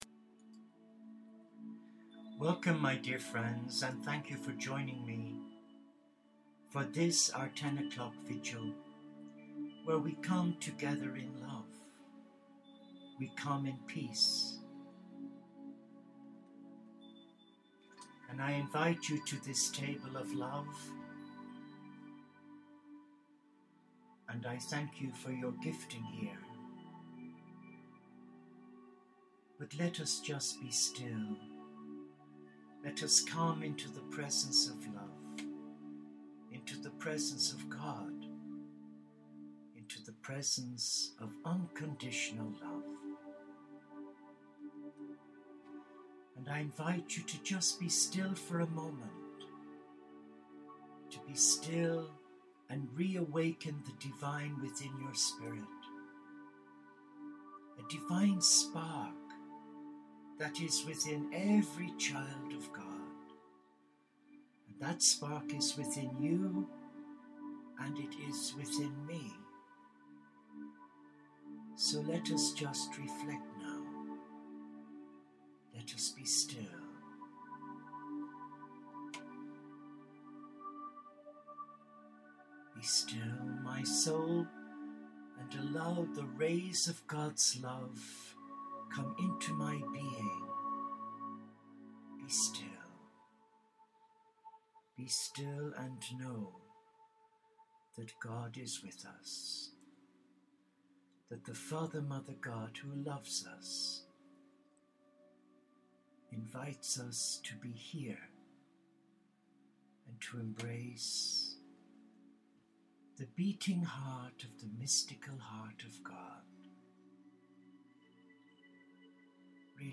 Our vigil focused more on Finding who we are and embracing the Heart by Trusting The Divine Masculine (Jesus) and the Divine Feminine (Mary Magdalene) to self heal. Our vigil concluded with a short guided meditation under the Lilac tree in our Monastery Garden.